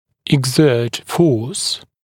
[ɪg’zɜːt fɔːs][иг’зё:т фо:с]прилагать силу